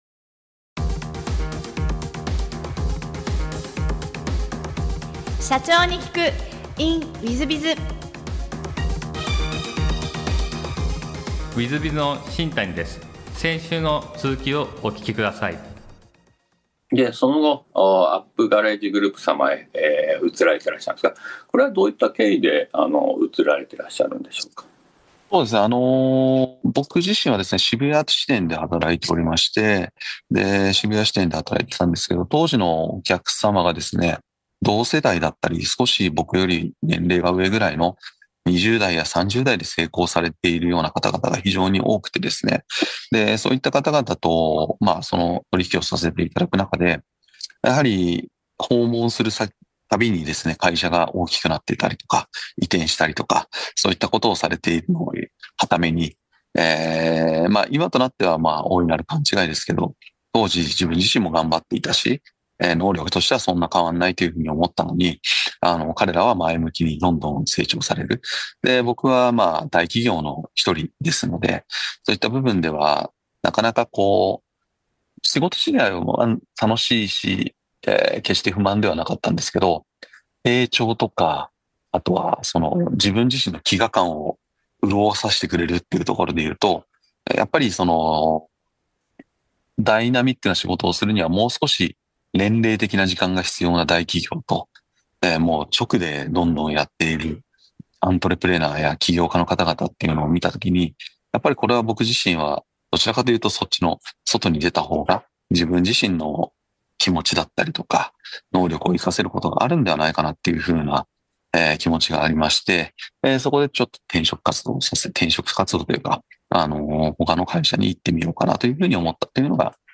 中古車業界で業績を伸ばし続けるお姿から経営のヒントが得られます。ぜひ、インタビューをお聞きください。